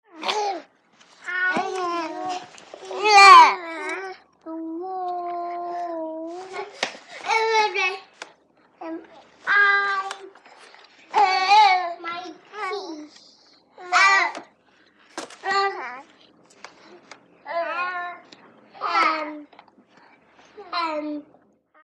Toddlers|Interior | Sneak On The Lot
CROWD - CHILDREN TODDLERS: INT: Group in a room, babbling & playing.